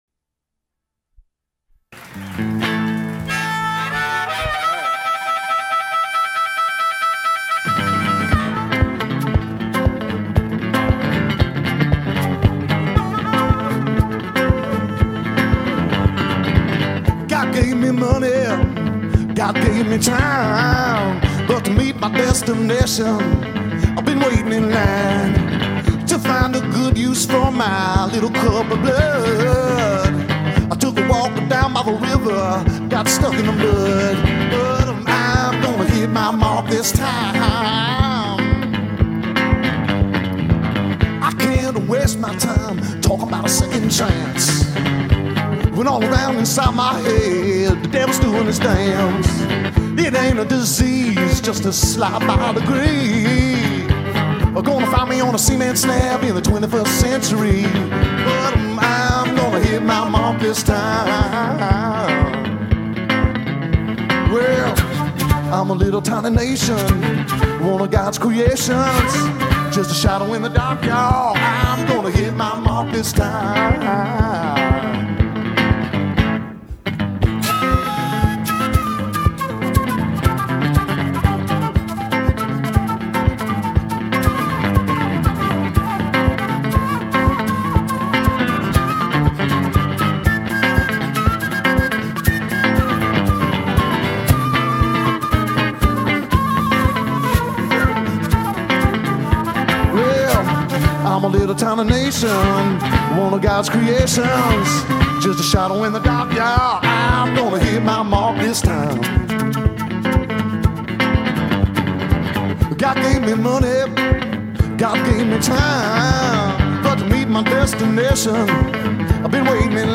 Live at the Bottom Line